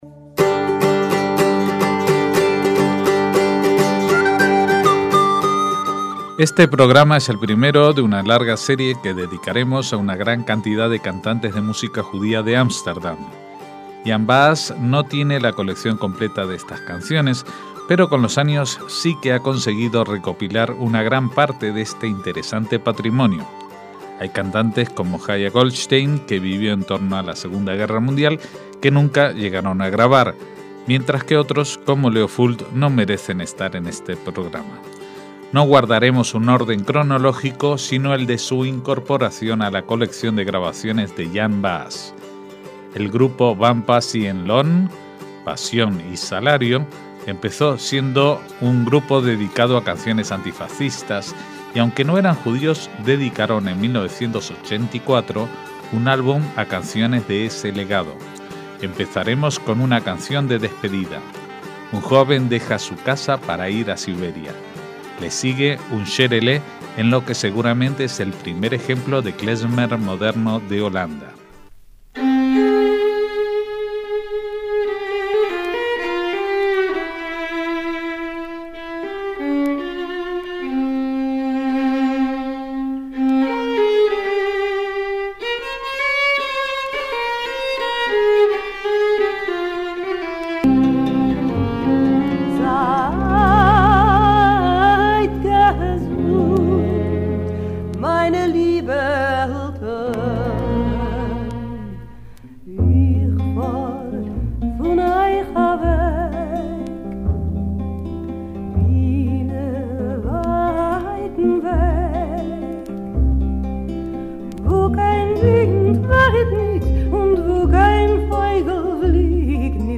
Esta es la primera de las entregas que dedicaremos a cantantes judíos de Ámsterdam a través de los tiempos.